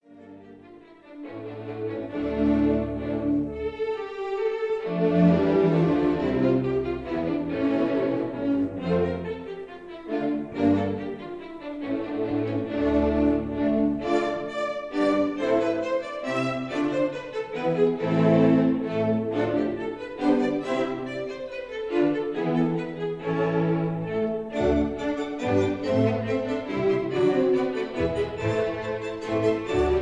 Basse-Danse, Allegro moderato
Pavane, Allegretto, ma un poco lento
Tordion, Con moto
Bransles, Presto
Pieds-en-lair, Andante tranquillo
Mattachins , Allegro con brio
Recorded in No. 1 Studio, West Hampstead,